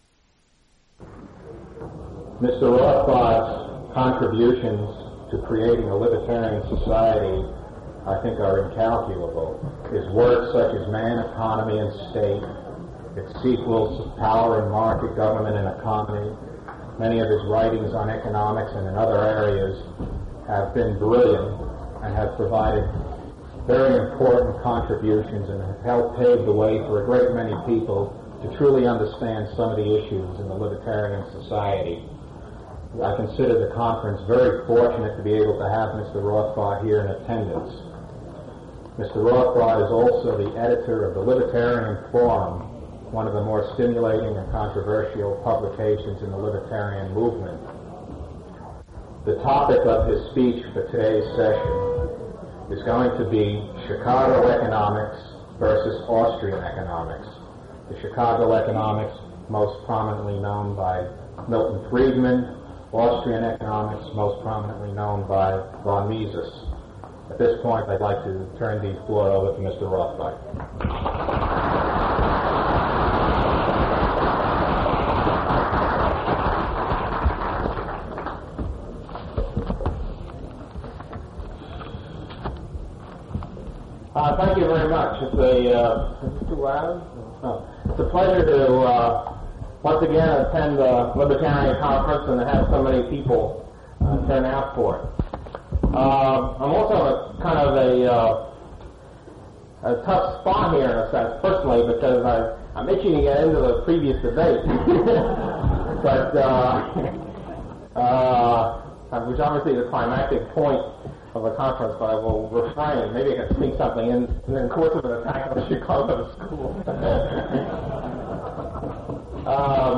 (The following 8000+ word transcript was difficult to make due to recording quality, tape deterioration and Rothbard’s speed.